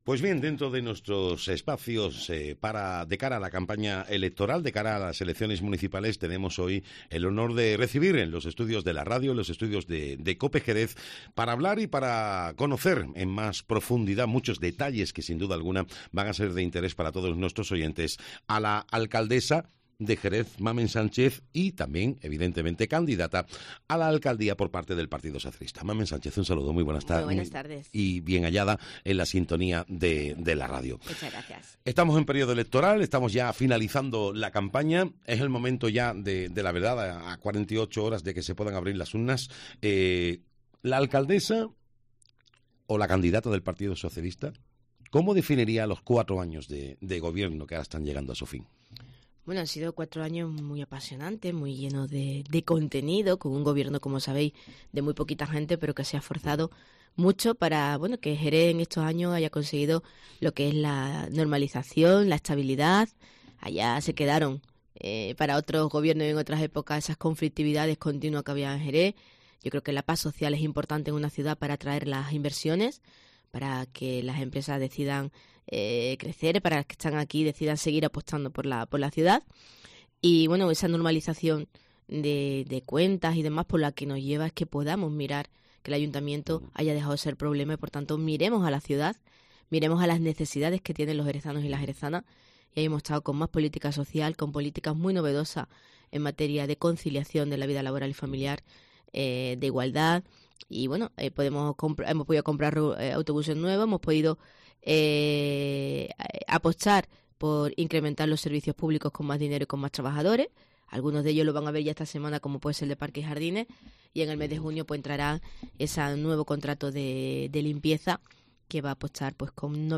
Entrevista Mamen Sánchez, candidata del PSOE y alcaldesa de Jerez